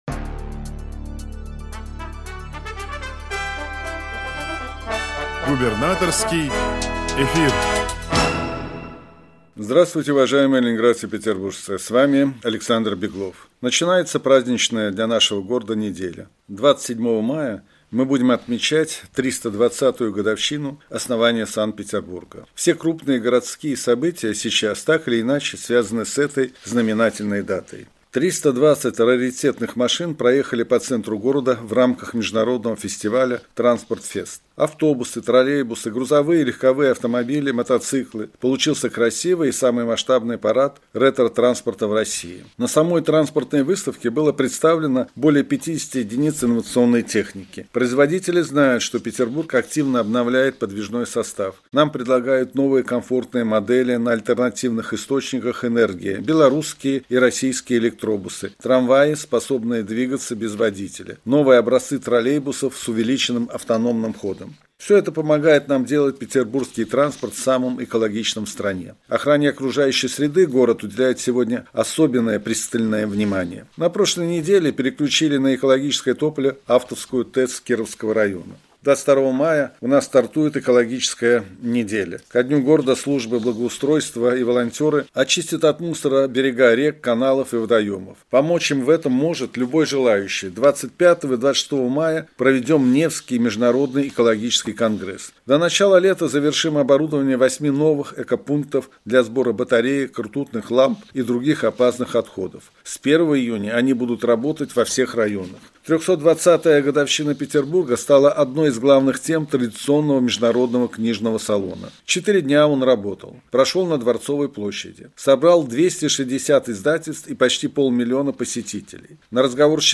Радиообращение – 22 мая 2023 года